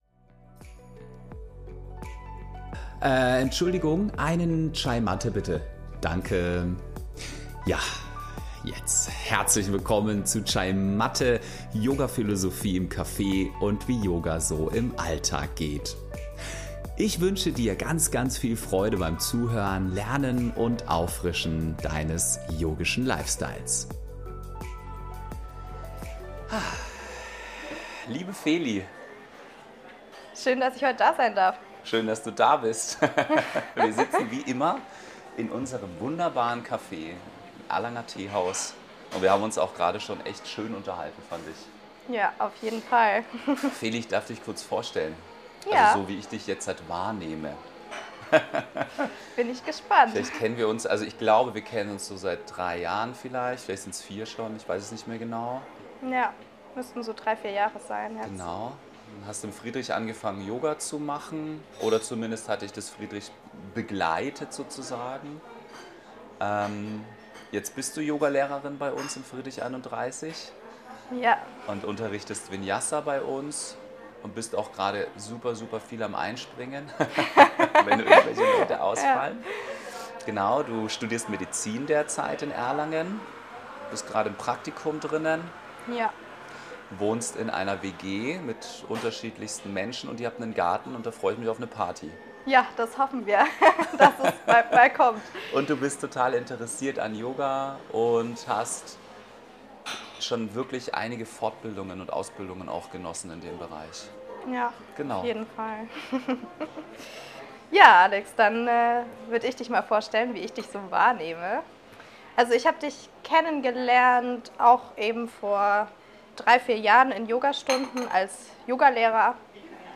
Im Gespräch unterhalten wir uns heute über die drei Arten der Wahrnehmung: a) die Wahrnehmung über eine externe Quelle, b) die Wahrnehmung über den Intellekt und c) die unbeeinflusste Wahrnehmung.